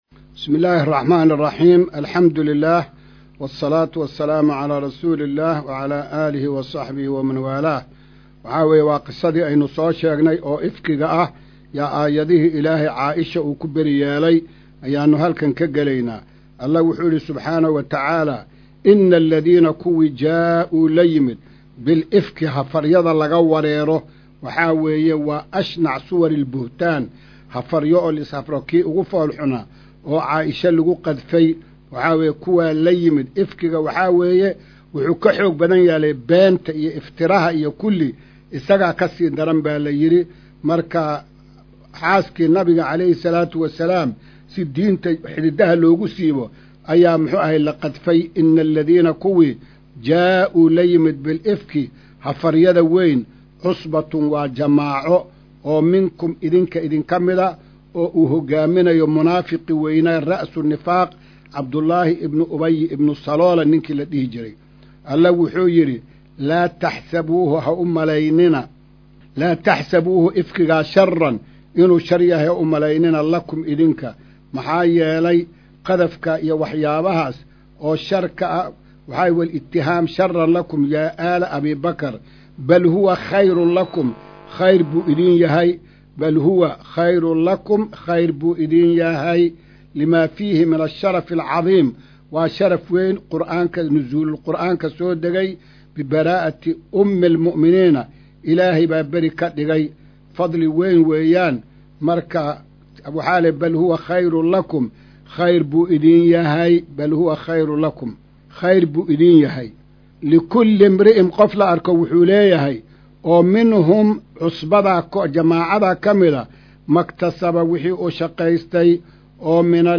Casharka-170aad-ee-Tafsiirka.mp3